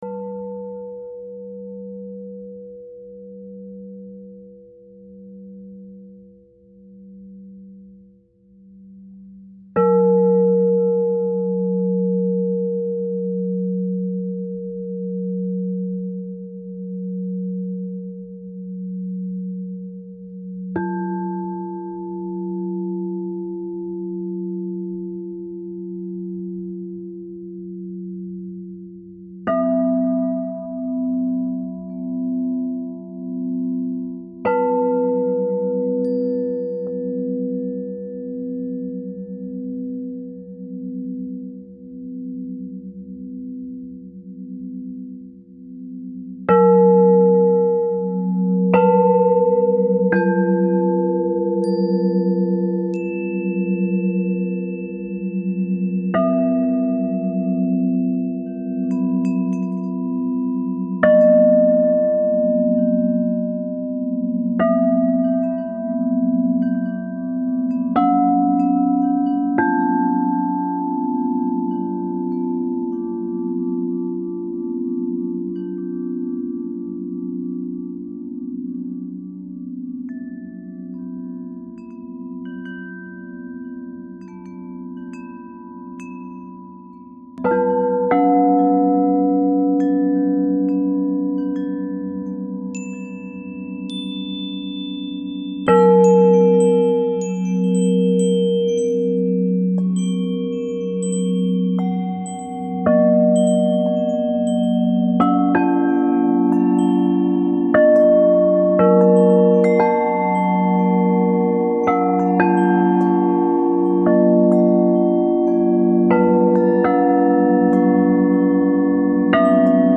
Meditational CD release